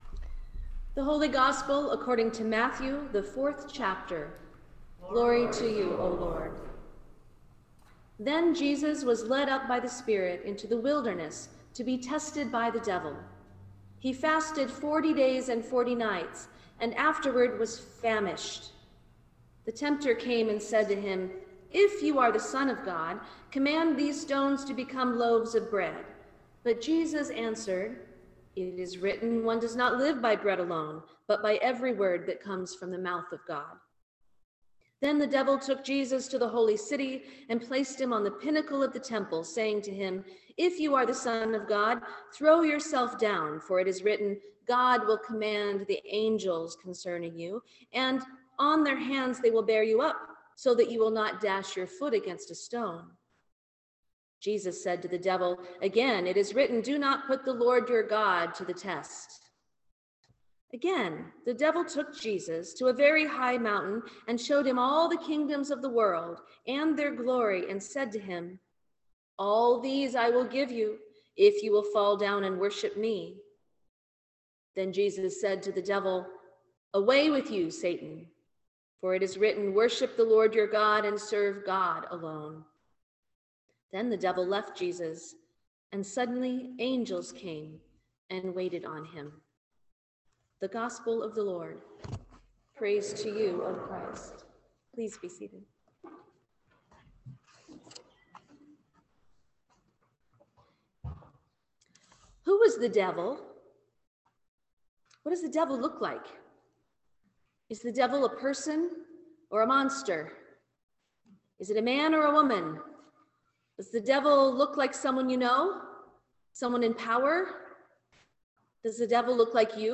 Sermon for the First Sunday in Lent 2023